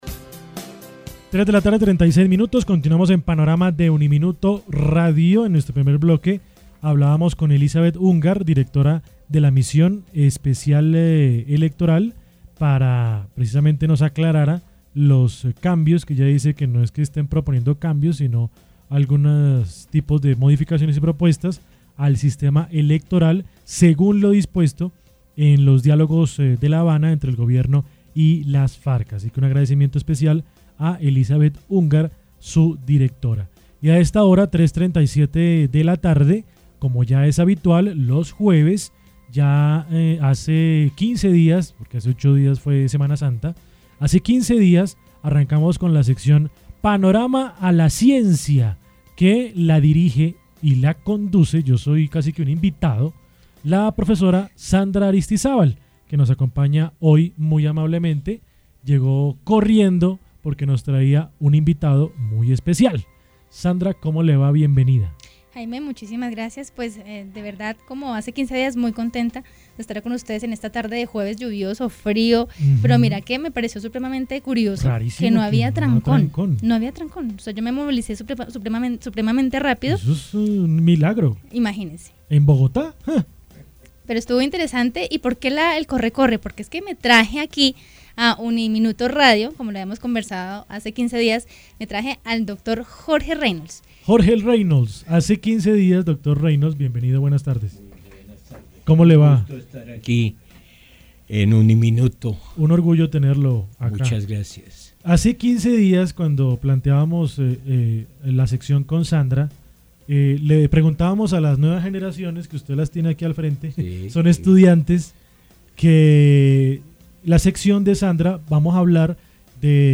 En diálogo con UNIMINUTO Radio estuvo el ingeniero colombiano Jorge Reynolds, creador del primer marcapasos y quien implantará en los próximos días el nanomarcapasos en un ser humano.